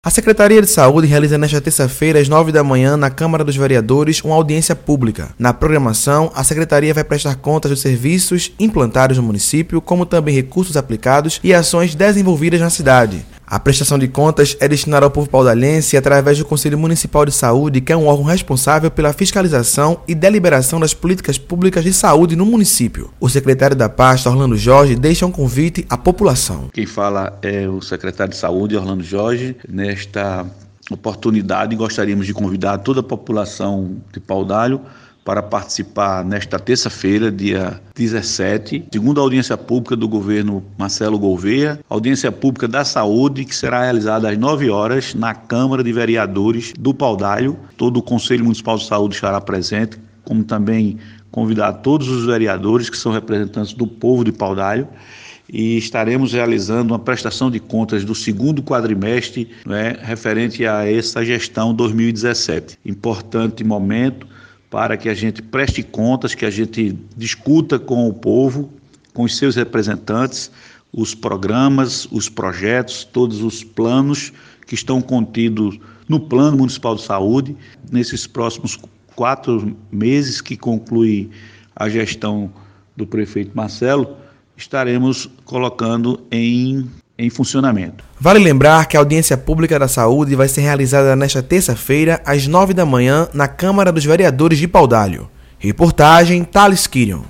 O secretario de Saúde do município, Orlando Jorge, deixou um convite à população.